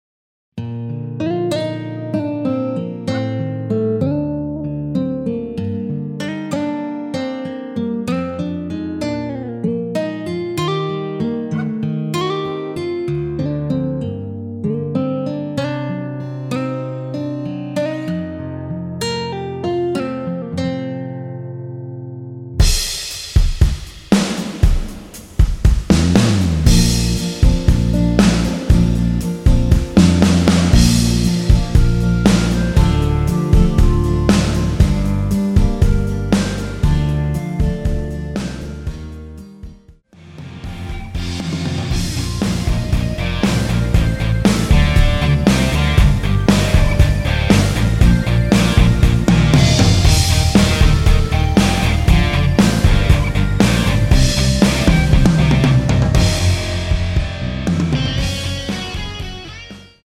원키에서(-2)내린 멜로디 포함된 MR입니다.
Bb
앞부분30초, 뒷부분30초씩 편집해서 올려 드리고 있습니다.
중간에 음이 끈어지고 다시 나오는 이유는